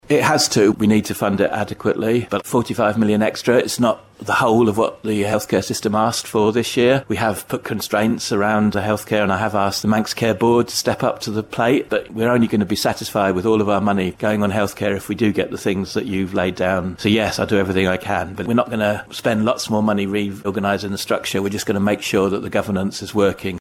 At Manx Radio's Budget Special , Chris Thomas MHK was asked whether we'd start to see improvements in access to GPs, surgical appointments and dentists as a result.